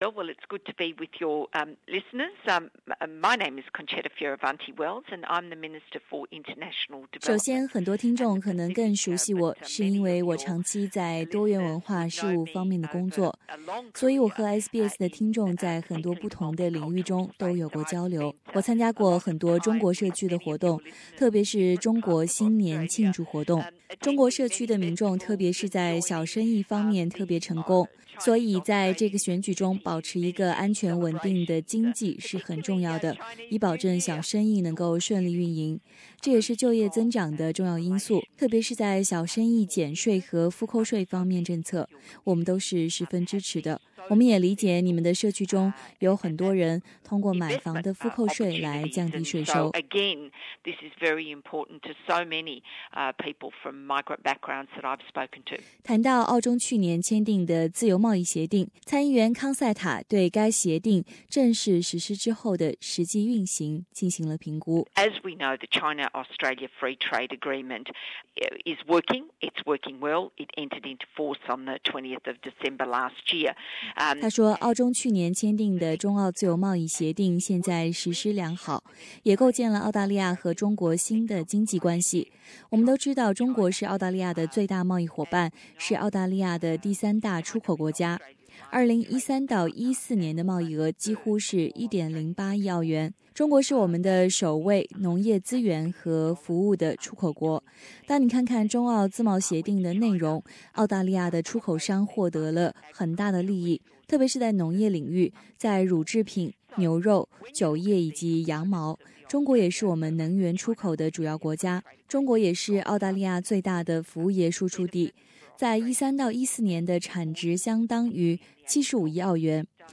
康赛塔也对大选宣传期间，流传的关于中国公司投资项目外带劳工的说法进行了澄清。以下请听详细的采访内容。